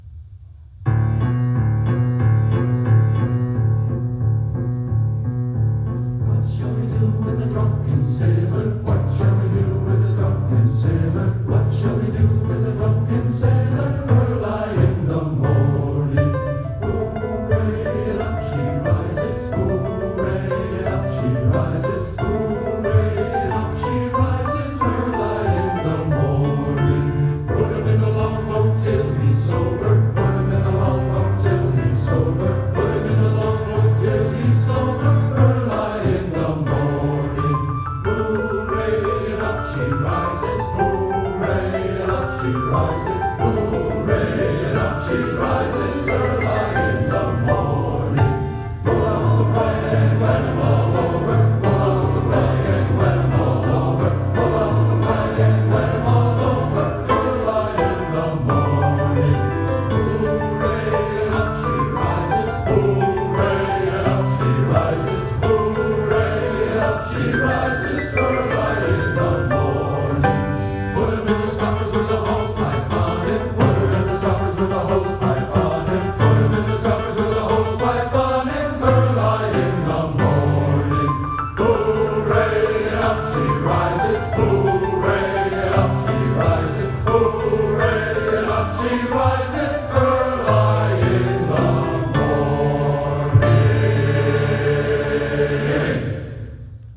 First Congregational Church Of Southington, Connecticut - April 22, 2023